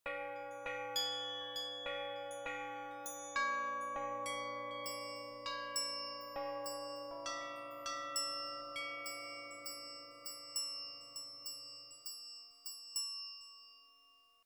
• 16 Ziehglocken (pully bells – vielleicht weiß unter unseren Lesern jemand, was das auf Deutsch ist)
Die Glocken überraschen mit einem schönen, reinen, zarten und tonal spielbaren Klang, wie ich ihn bislang in kaum einer Autowerkstatt gehört habe – sehr angenehm.
Wirklich exzellent aufgenommen, diese Ziehglocken. Die sind so gut, dass sie auch in modernen Klassik-Kompositionen oder im Jazz, wenn ganz feine, dynamisch differenzierte Sounds gefragt sind, eine Chance hätten.